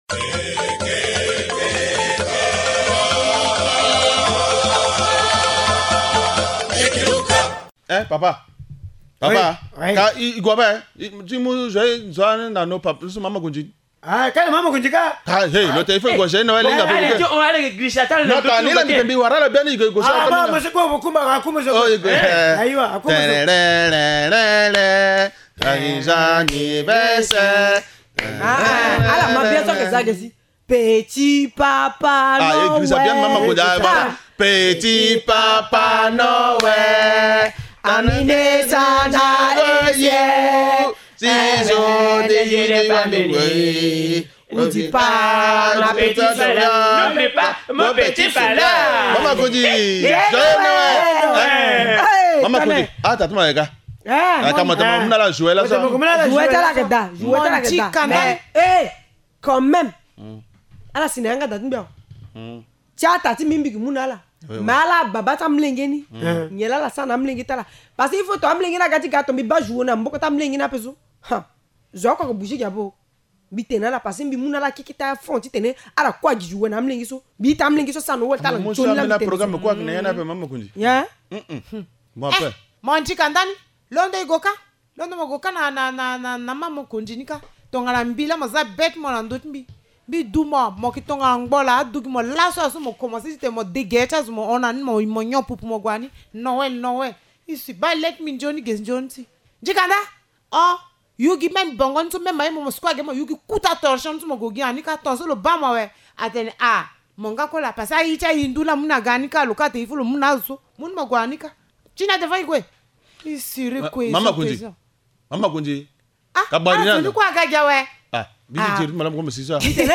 Linga théâtre : l’importance de la célébration de Noël au village